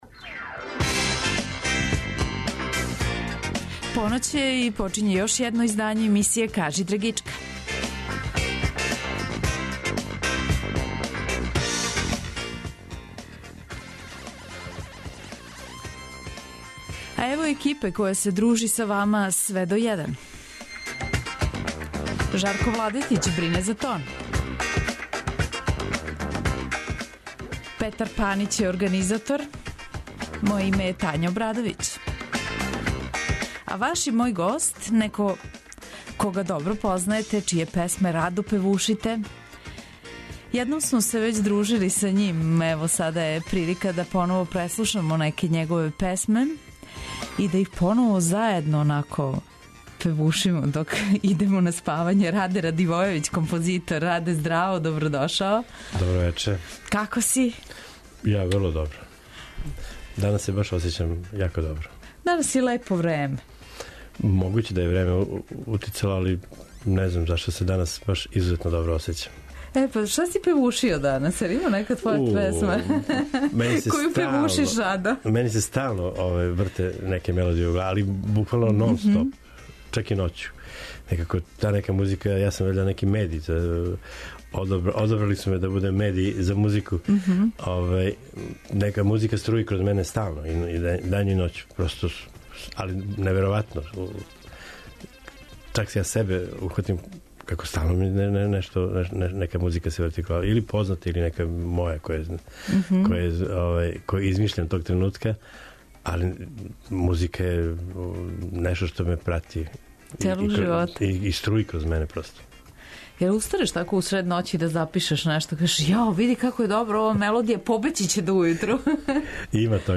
Слушаоци ће имати прилике да чују, уз његове велике хитове, и инстументале композиције које је урадио.